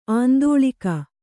♪ āndōḷika